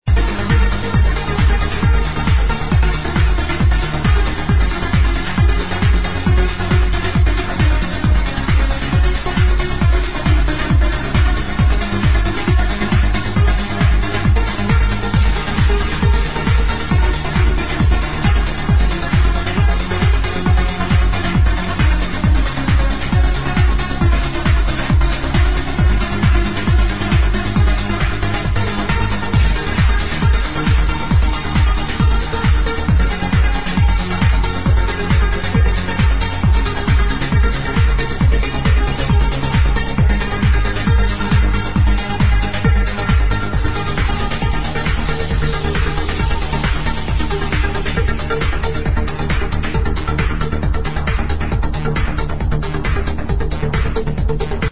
the track is from a the Liveset